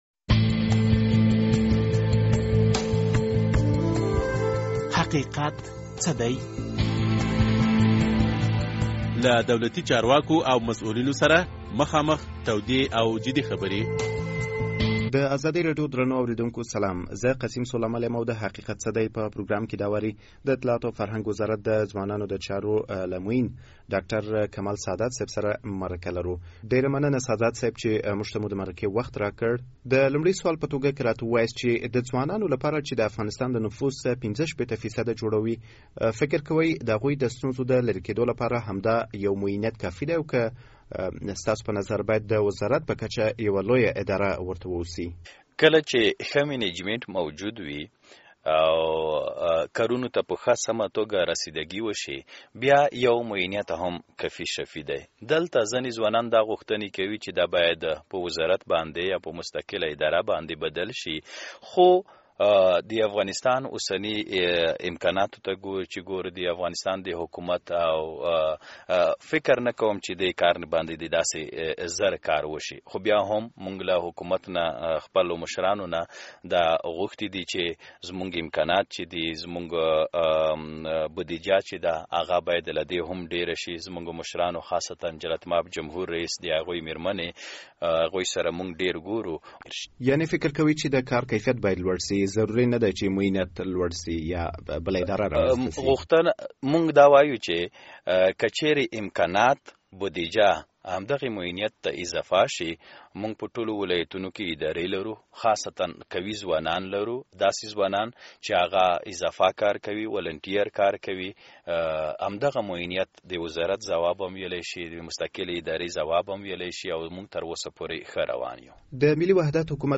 دا خبره د ځوانانو د چارو معین کمال سادات د ازادي راډيو د حقیقت څه دی له پروګرام سره مرکه کې وکړه.